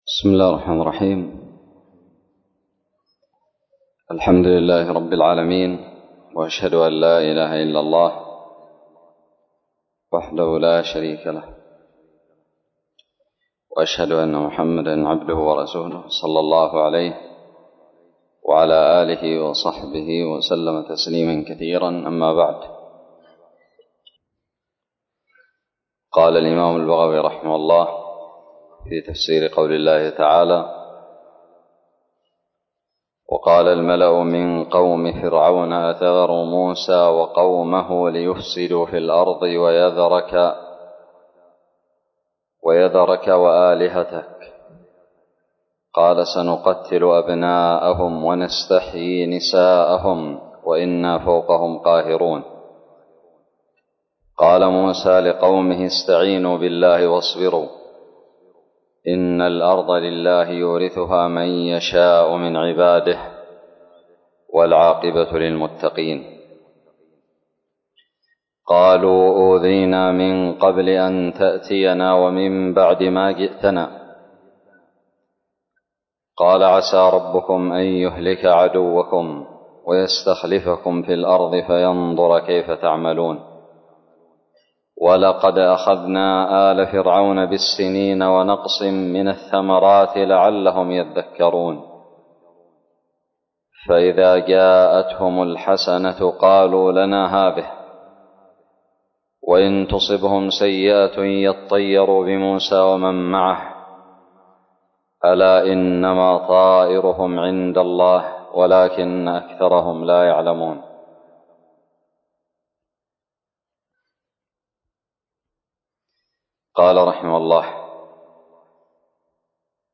الدرس السادس والعشرون من تفسير سورة الأعراف من تفسير البغوي
ألقيت بدار الحديث السلفية للعلوم الشرعية بالضالع